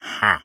Minecraft Version Minecraft Version 1.21.5 Latest Release | Latest Snapshot 1.21.5 / assets / minecraft / sounds / mob / illusion_illager / idle2.ogg Compare With Compare With Latest Release | Latest Snapshot